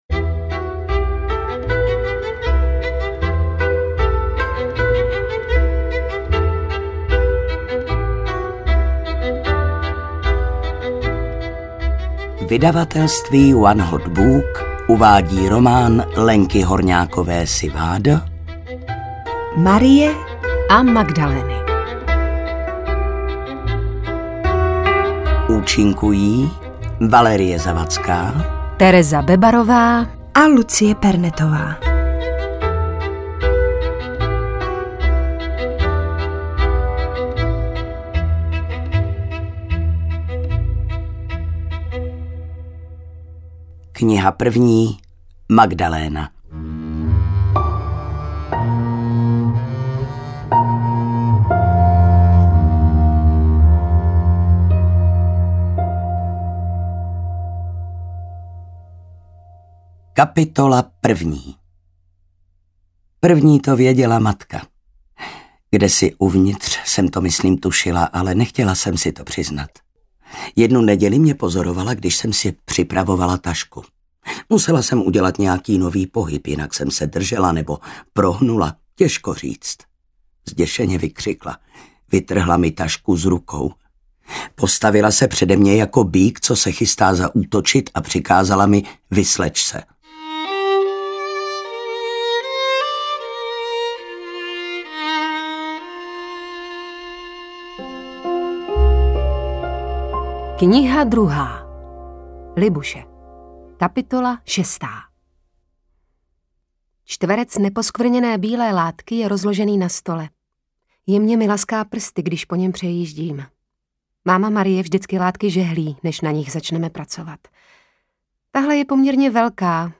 Ukázka z knihy
marie-a-magdaleny-audiokniha